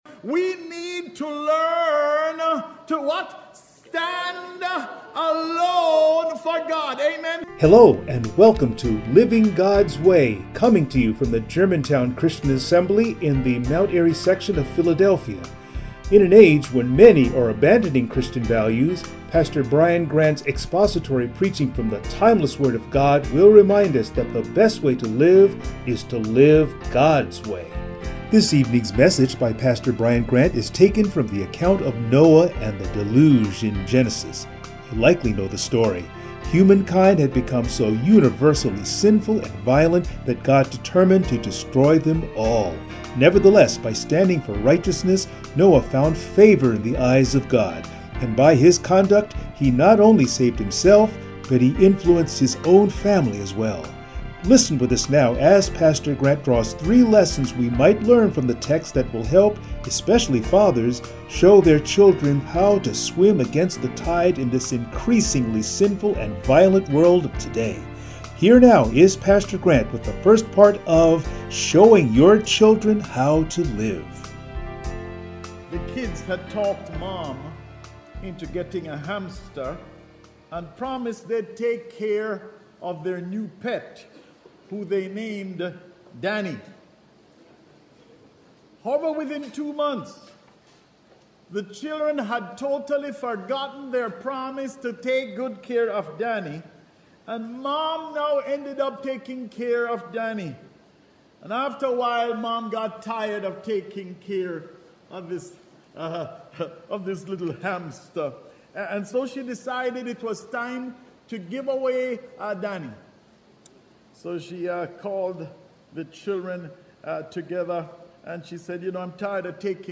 Passage: Genesis 6:1-22 Service Type: Sunday Morning